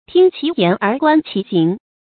听其言而观其行 tīng qí yán ér guān qí xíng
听其言而观其行发音